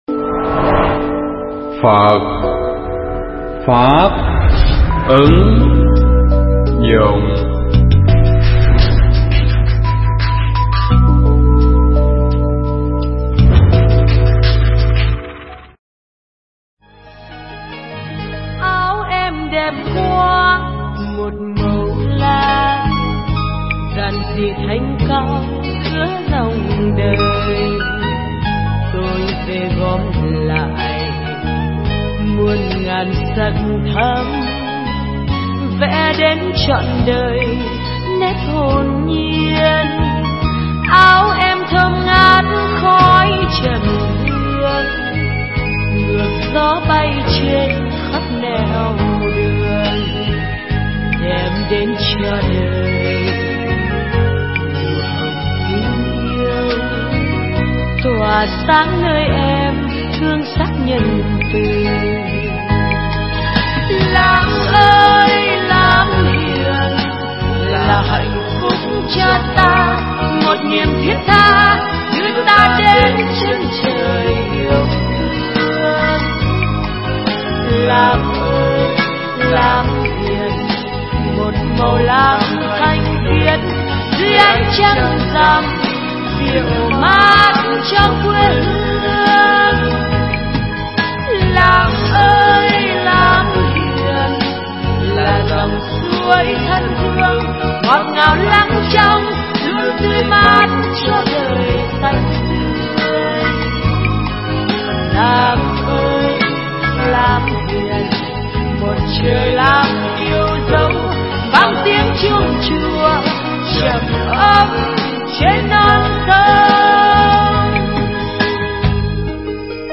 Nghe mp3 thuyết Pháp Nghệ Thuật Của Hạnh Buông Xả (KT10)
trong khoá tu Một Ngày An Lạc lần thứ 10 tại tu viện Tường Vân